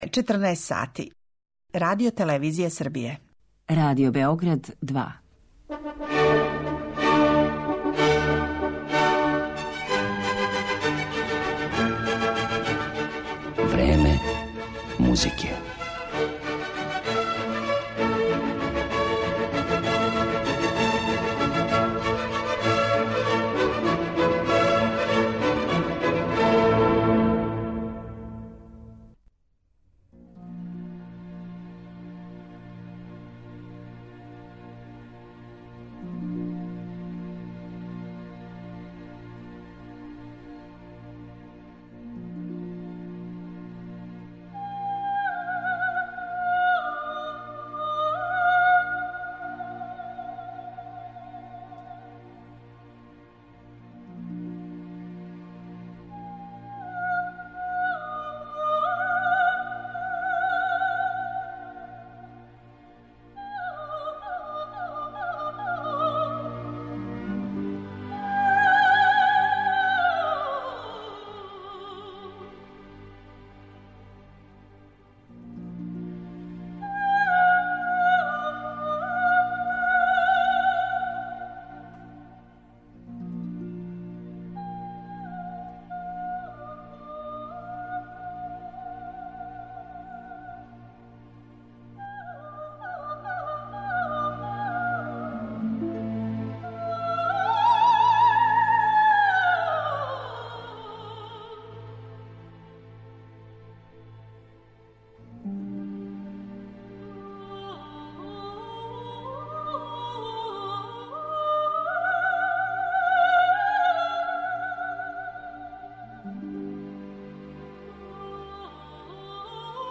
Емисија је посвећена ВОКАЛИЗИ, једном сасвим посебном жанру вокалне музике, оном у коме људски глас, по неписаном правилу сопран, пева мелодију без текста, изговарајући самогласнике.